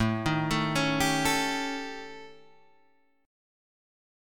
A 7th Flat 5th